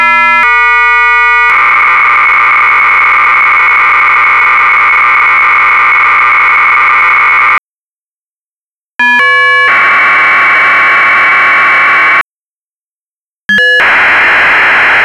AFSK_paging_link_ex_with_all_baud_rates.wav